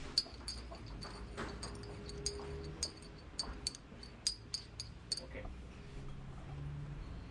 咖啡杯和勺子 05
描述：陶瓷咖啡杯和金属匙子
标签： 咖啡 勺子
声道立体声